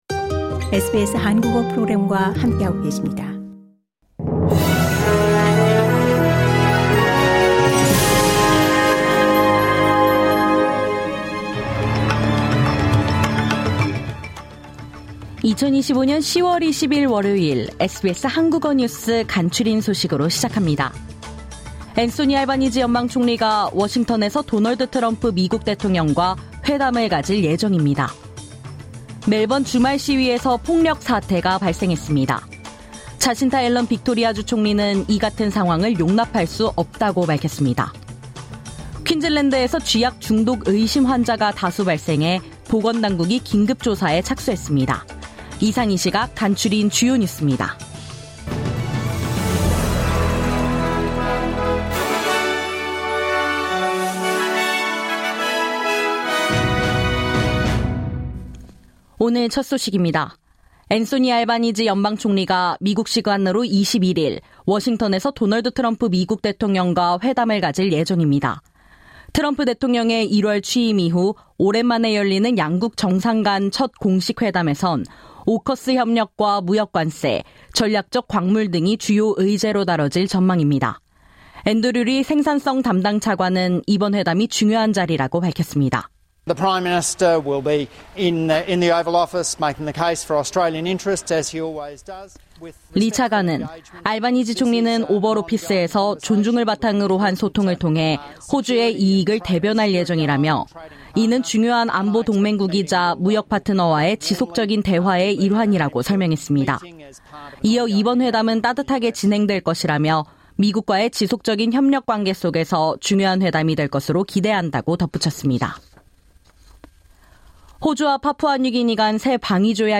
매일 10분 내로 호주에서 알아야 할 뉴스를 한국어로 정리해 드립니다.